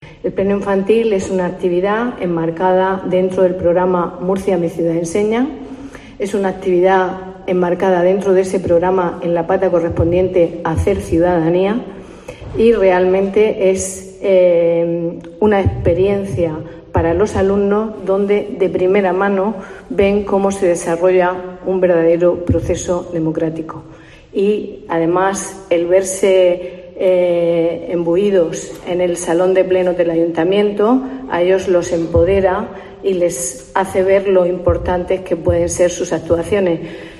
Belén López, concejala de Educación